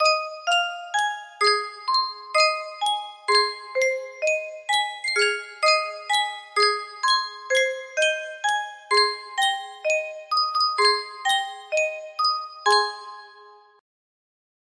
Sankyo Music Box - A Hot Time in the Old Town Tonight BEK music box melody
Full range 60